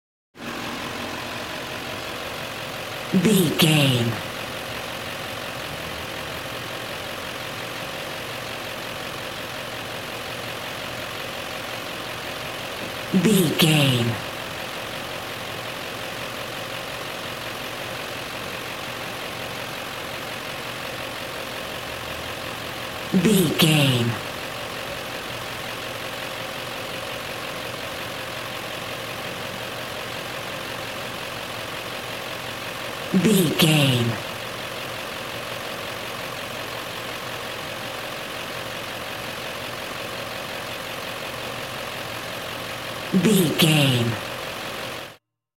Ambulance Ext Diesel Engine Idle Distant
Sound Effects
urban